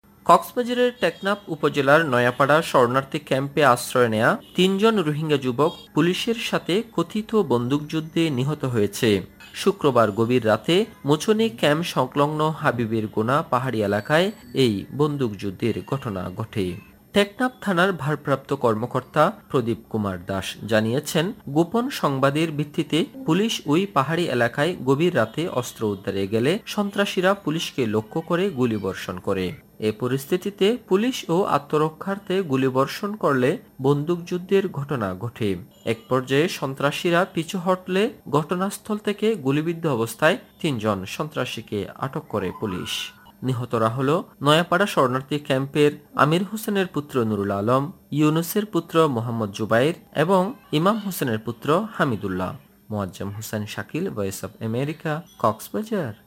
ঘটনাস্থল থেকে পুলিশ ৪টি দেশীয় তৈরি অস্ত্র ও ৭টি কার্তুজ জব্দ করেছে। কক্সবাজার থেকে জানাচ্ছেন